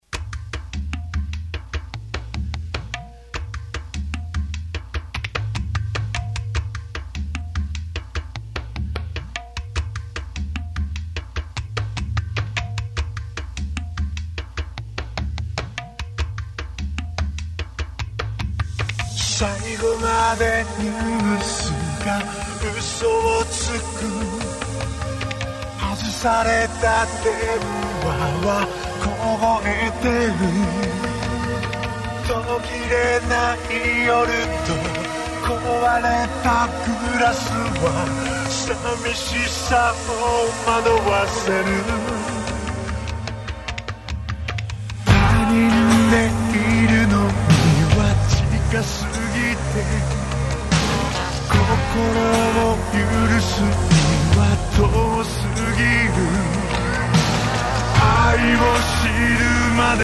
結果：これなら聴けるレベルかな？ → チョット成功気味